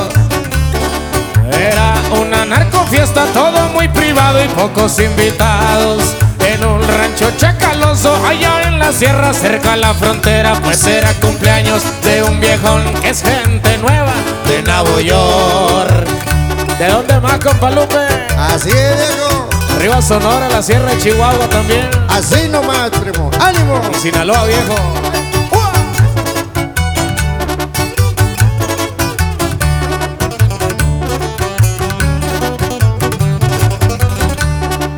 # Regional Mexican